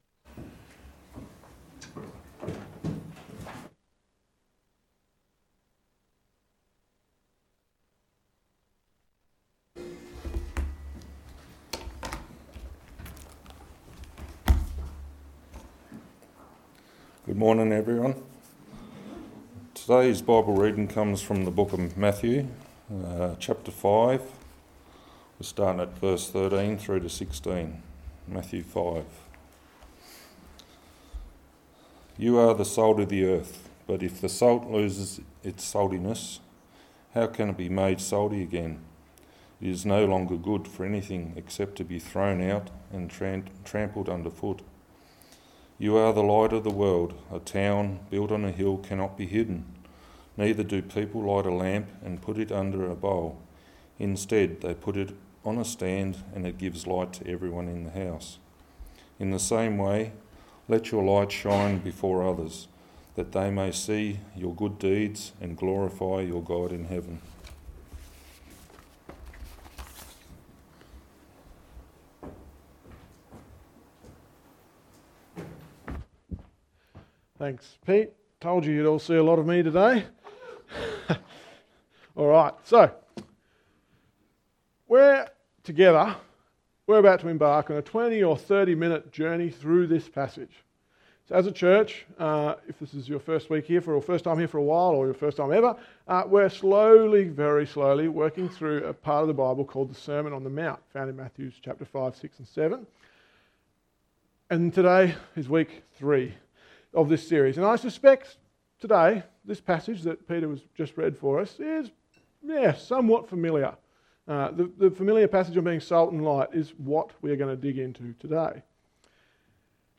Text: Matthew 5: 13-16 Sermon